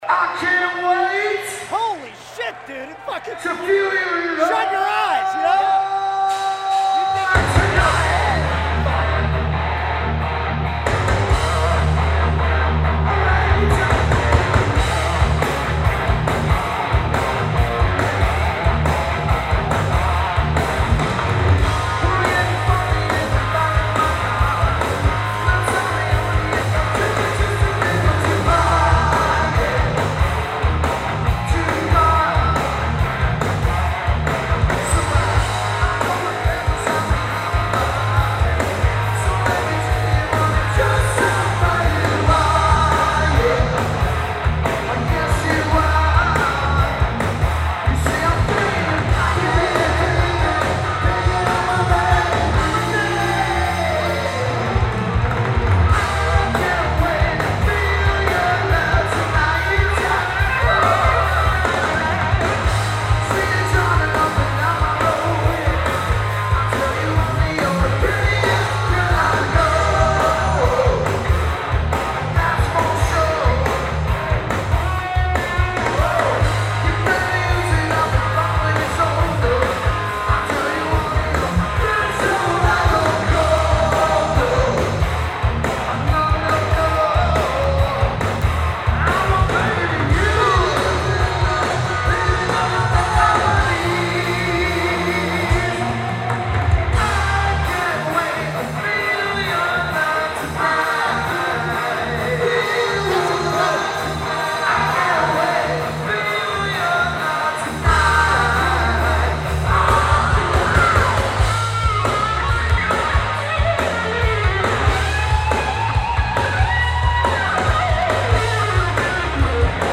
Genre: Hard Rock.